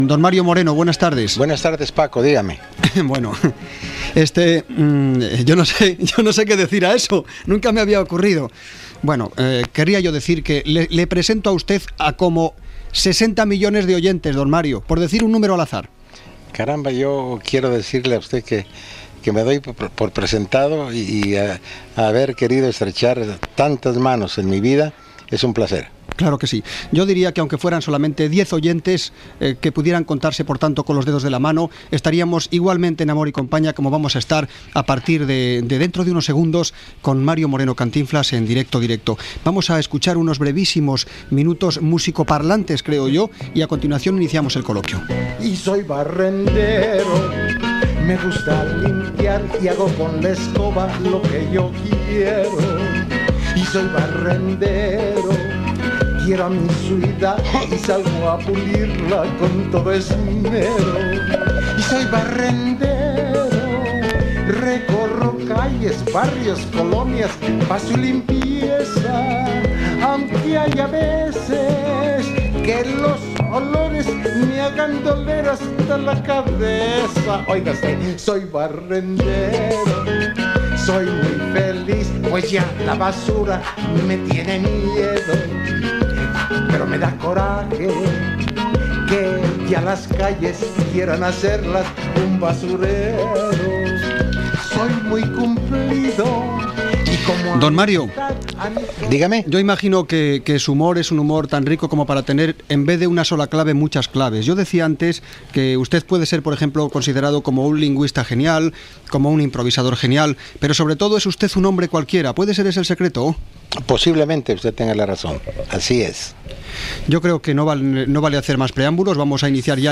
Descripció Presentació de l'invitat Mario Moreno "Cantinflas", cançó, preguntes telefòniques de l'audiència a l'actor Gènere radiofònic Entreteniment